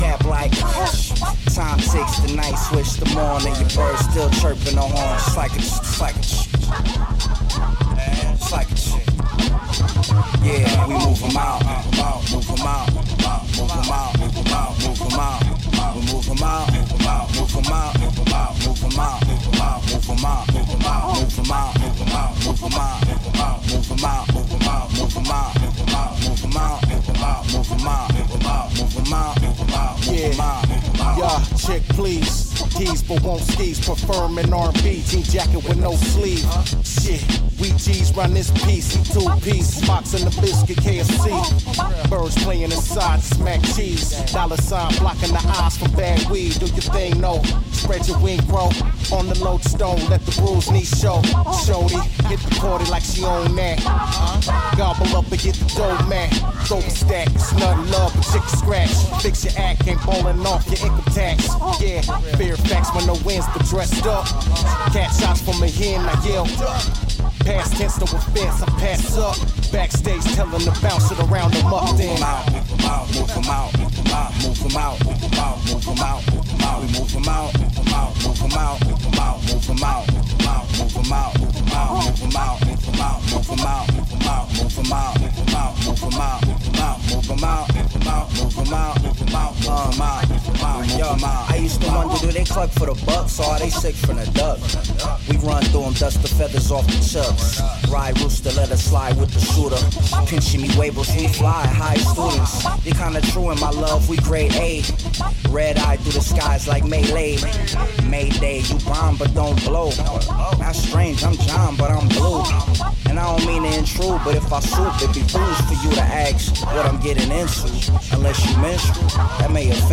collaborative studio album by rappers
Hip Hop Funk